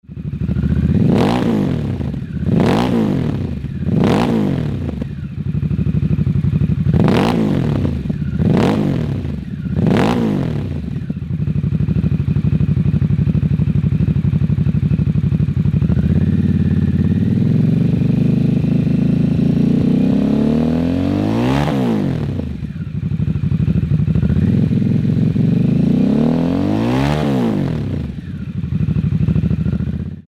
The S&S Grand National slip-on for the Nightster compliments that styling while letting the modern engine breathe while adding a much needed exhaust note
Removeable dB reducer (sound level tunable) (J2825 compliant).Equipped with O2 Lambda sensor plug housing
S&S Cycle - 4-1/2" Grand National Slip-On Muffler - Nightster - Without dB Reducer